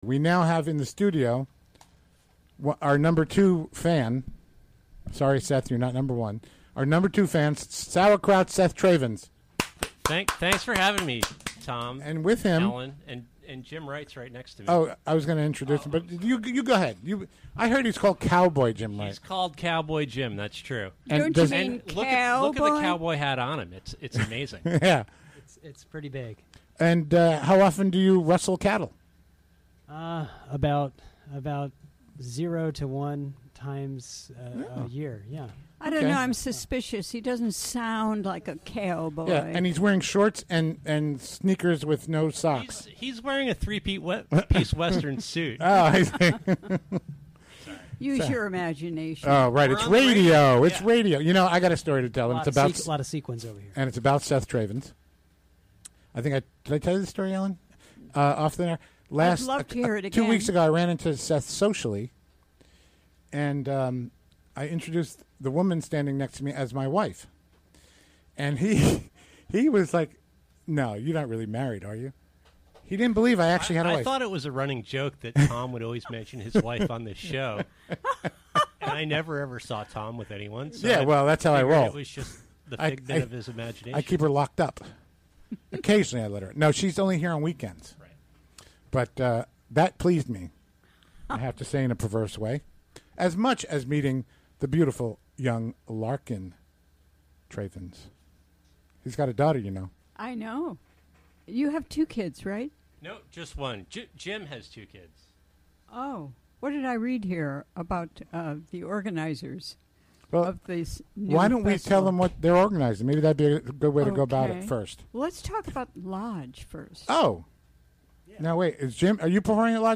Recorded during the WGXC Afternoon Show on Thursday August 11, 2016.